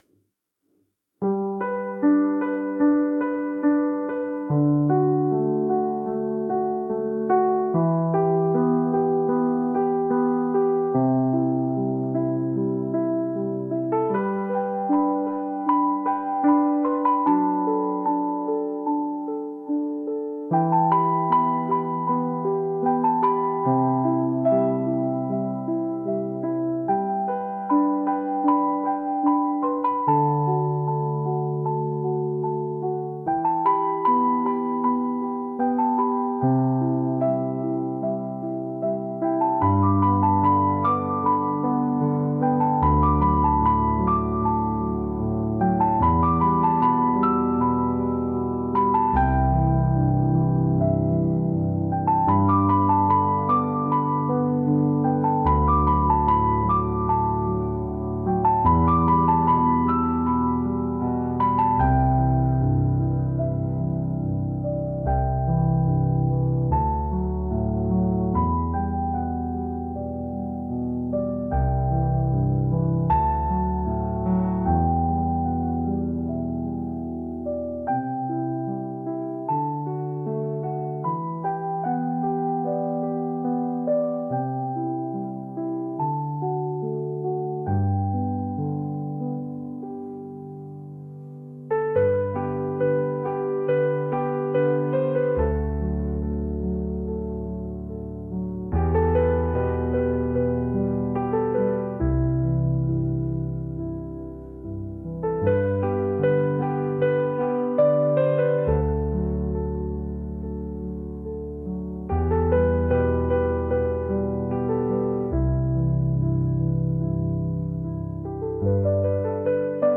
フリーBGM 🎶 静かな夜、星のきらめきと共に、遠い記憶がよみがえるような幻想的なアンビエントBGMです。